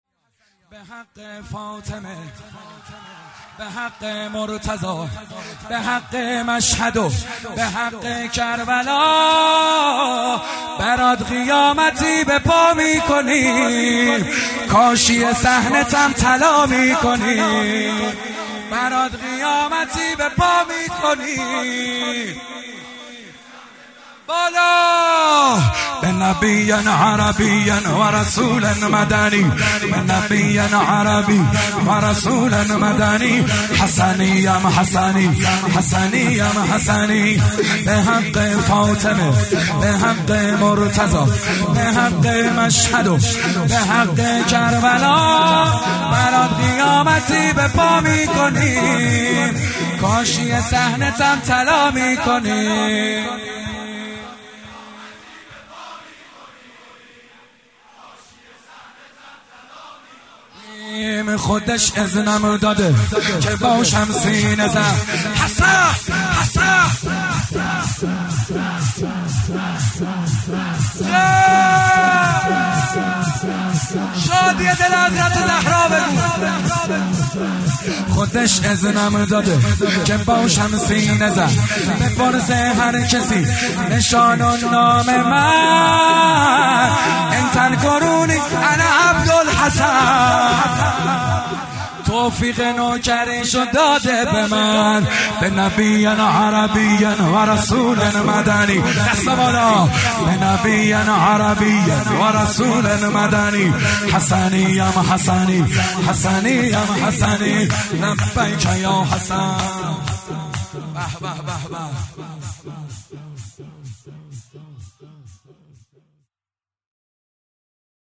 شور زیبا